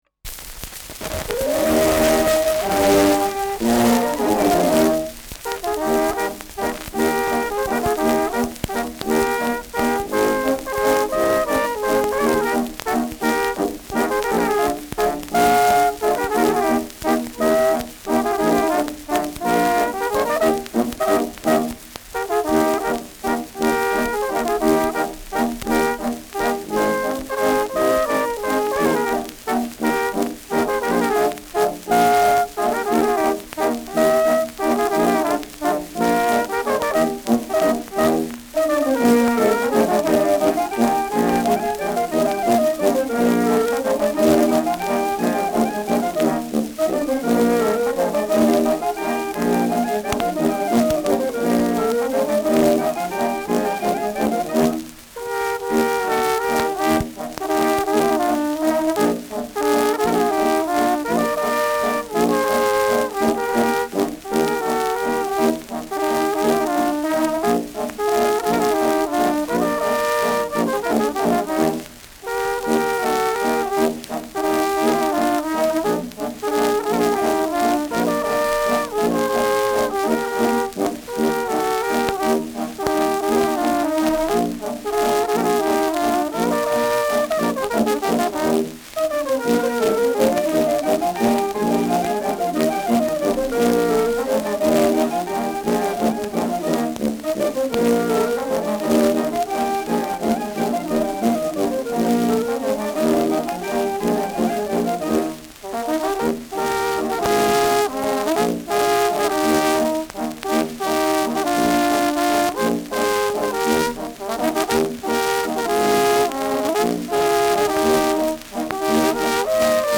Schellackplatte
präsentes Rauschen : leichtes Knistern : dumpfes Knacken zu Beginn : abgespielt : gelegentliches Knacken : leichtes Leiern : leichtes „Schnarren“ bei lauteren Trompetenstellen
Kapelle Peuppus, München (Interpretation)